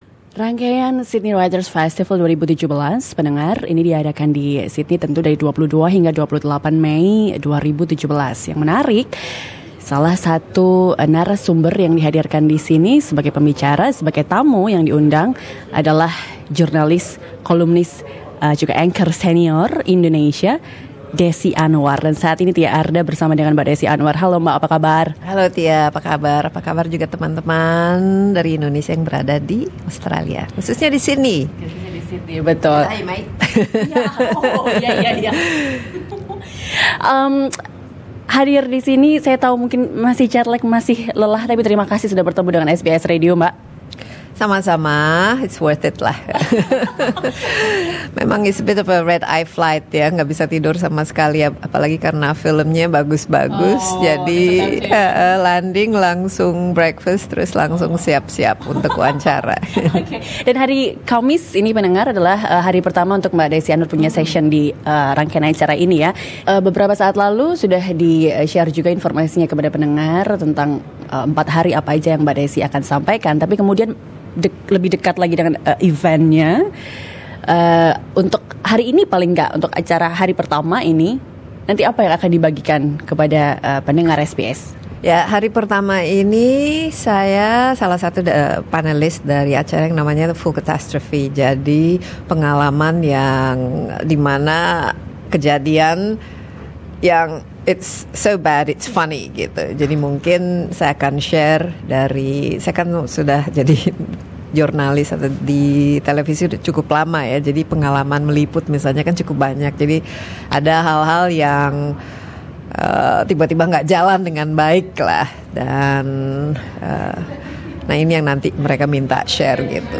Wawancara dengan wartawan senior Indonesia, Desi Anwar, sebagai salah satu penulis internasional dalam gelaran Sydney Writers' Festival 2017.